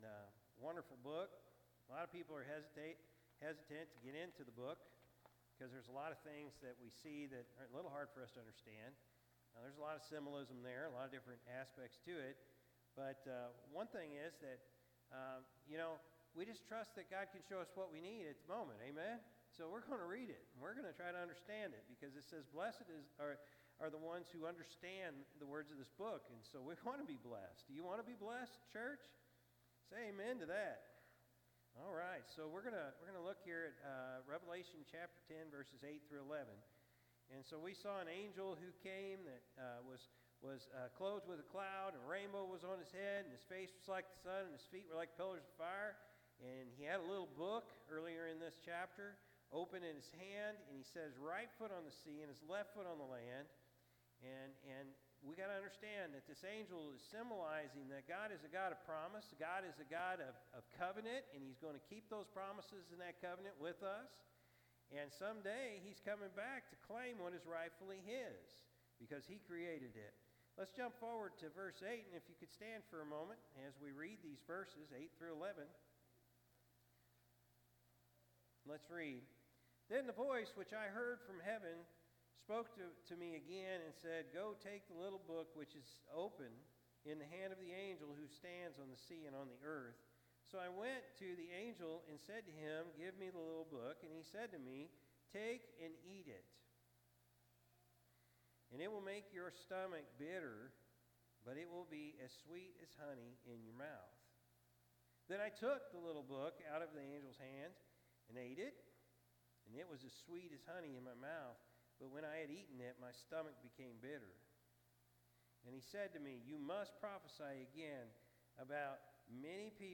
November-3-2024-Morning-Service.mp3